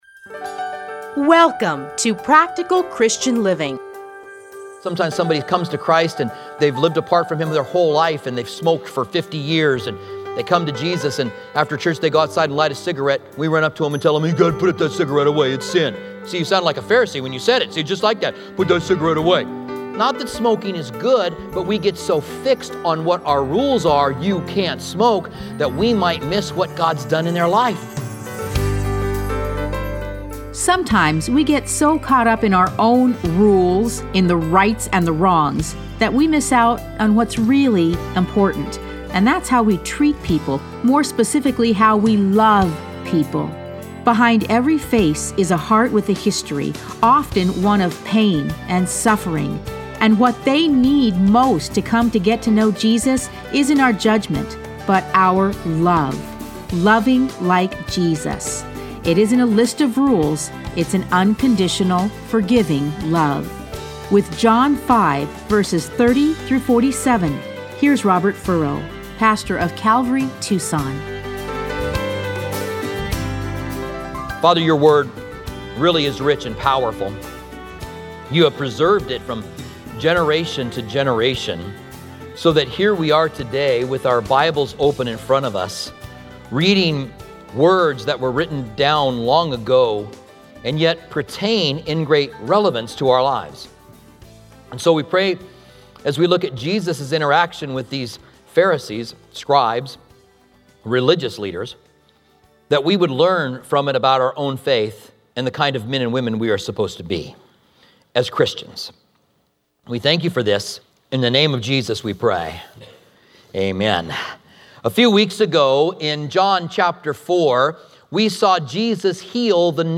Listen to a teaching from John 5:30-47.